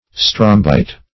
strombite - definition of strombite - synonyms, pronunciation, spelling from Free Dictionary Search Result for " strombite" : The Collaborative International Dictionary of English v.0.48: Strombite \Strom"bite\, n. (Paleon.) A fossil shell of the genus Strombus.